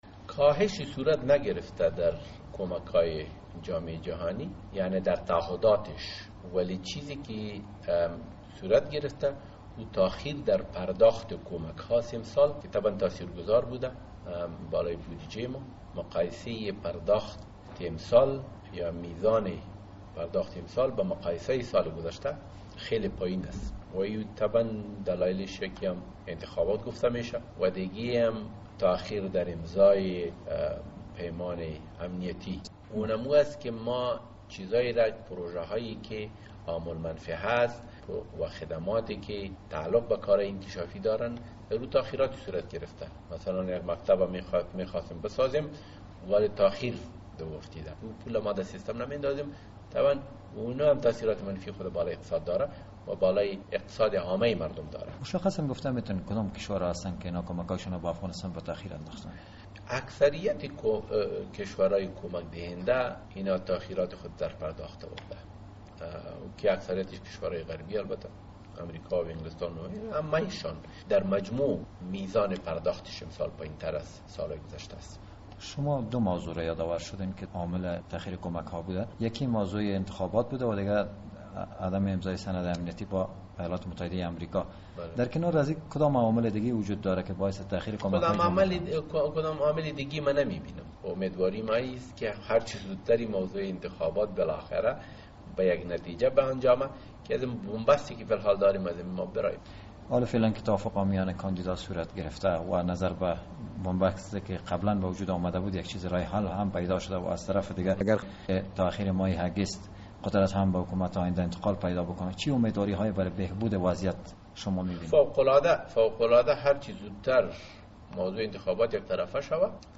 مصاحبه در مورد کاهش کمک های جامعه جهانی به افغانستان؟